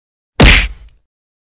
yumruk-sesi.mp3